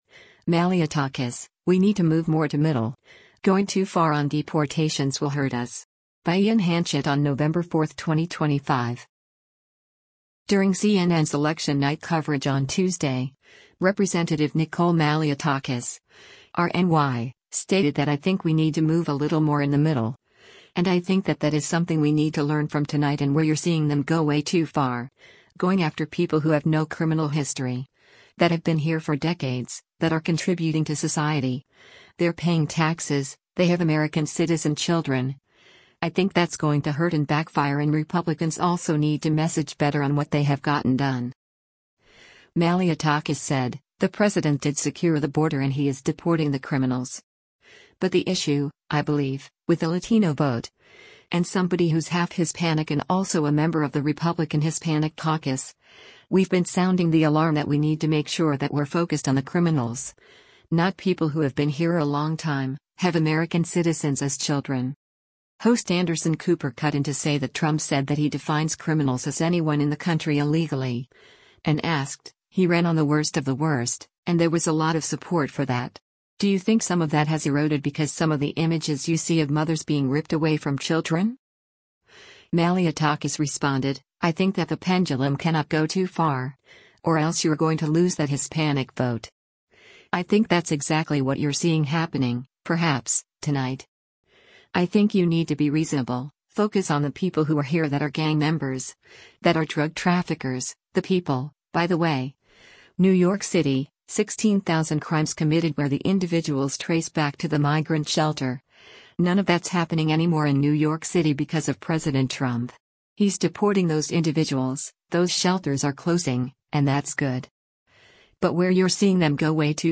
Host Anderson Cooper cut in to say that Trump said that he defines criminals as anyone in the country illegally, and asked, “he ran on the worst of the worst, and there was a lot of support for that. Do you think some of that has eroded because some of the images you see of mothers being ripped away from children?”